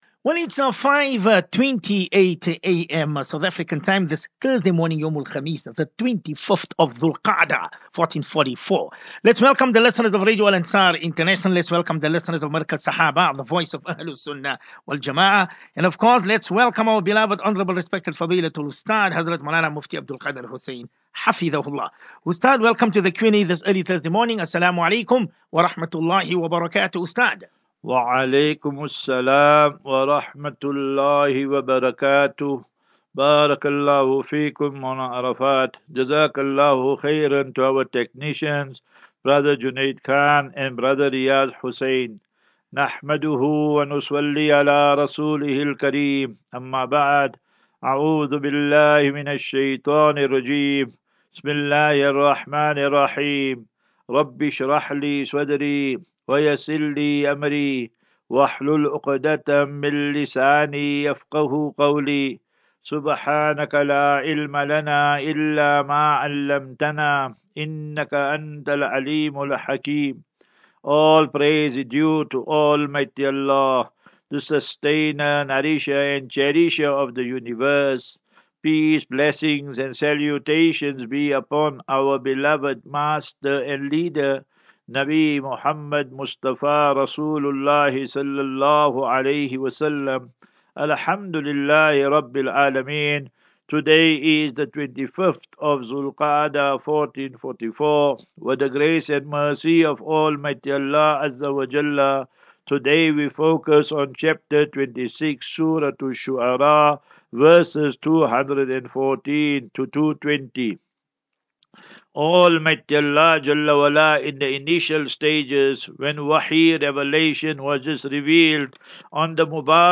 View Promo Continue Install As Safinatu Ilal Jannah Naseeha and Q and A 15 Jun 15 June 23 Assafinatu